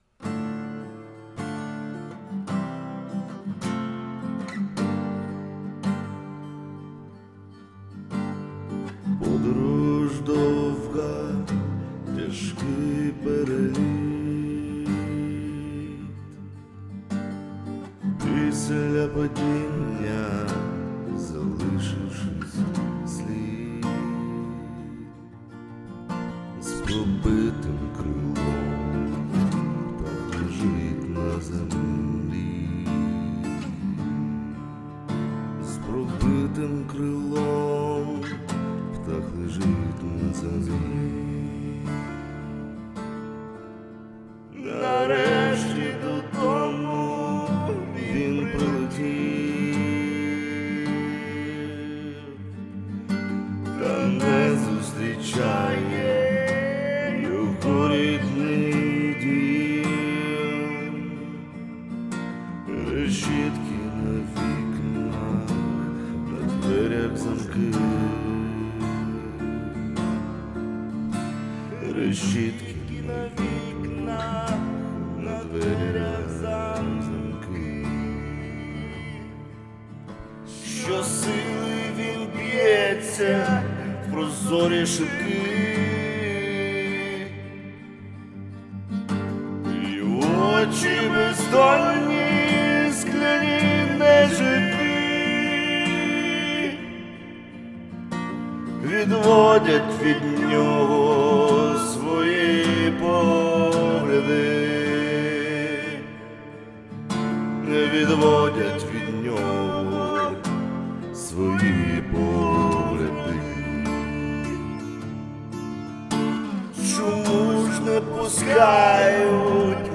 ТИП: Пісня
СТИЛЬОВІ ЖАНРИ: Ліричний
ВИД ТВОРУ: Авторська пісня
Чудова,зворушлива композиція. 16 16 16